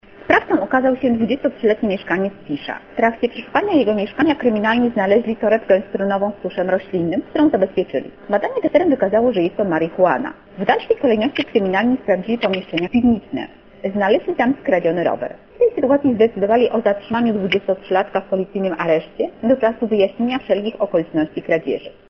Mówi